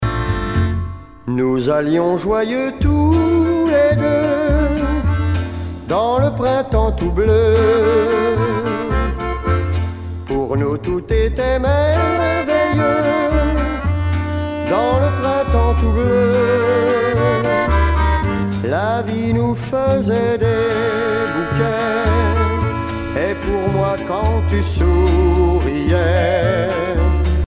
danse : slow fox
Pièce musicale éditée